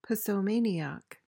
PRONUNCIATION:
(puhs-o-MAY-nee-ak)